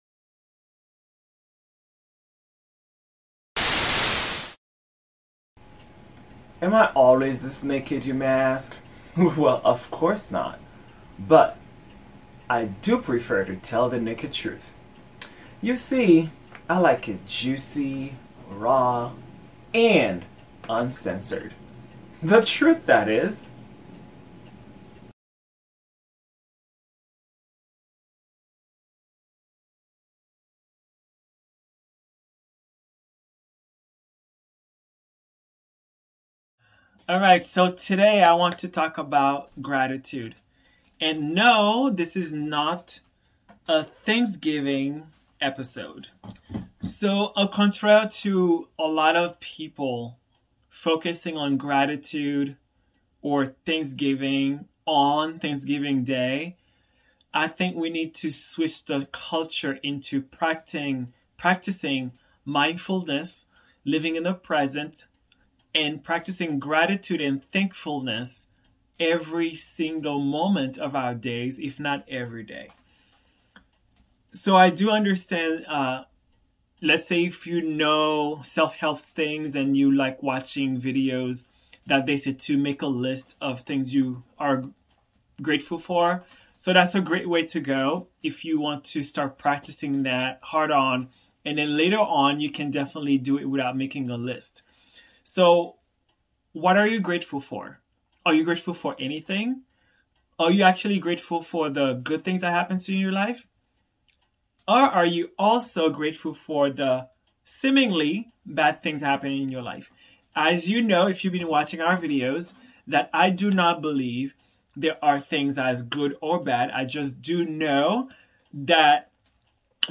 Solo.